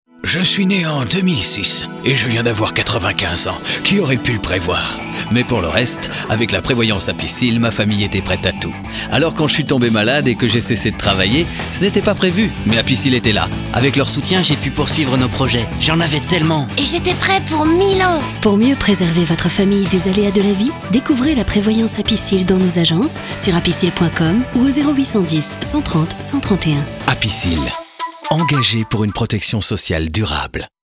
APICIL (morphing de voix)